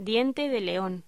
Locución: Diente de león
voz